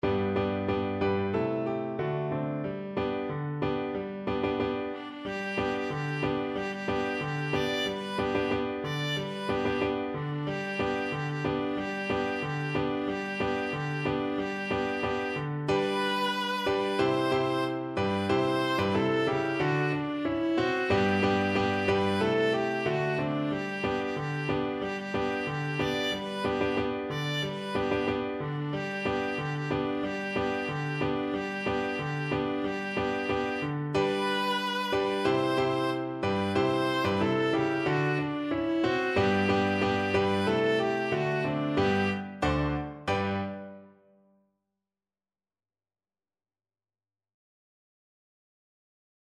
Viola
Traditional Music of unknown author.
2/2 (View more 2/2 Music)
Happily =c.92
G major (Sounding Pitch) (View more G major Music for Viola )